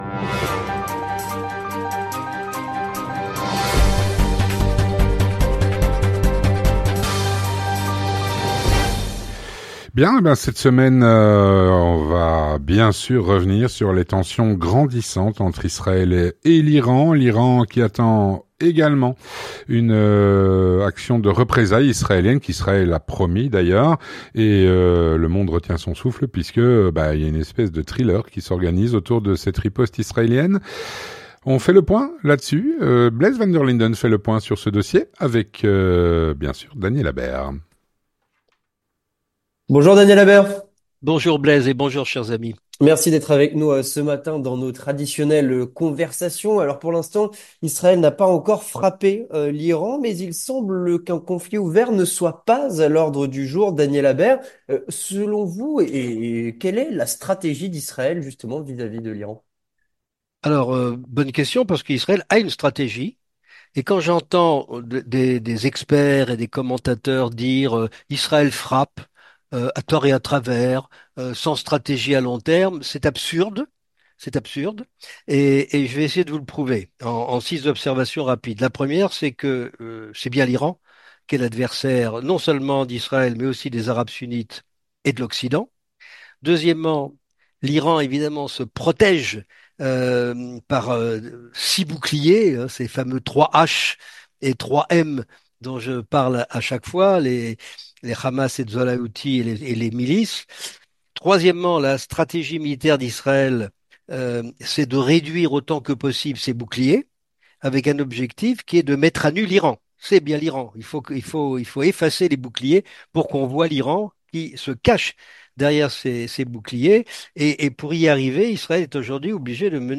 géopolitologue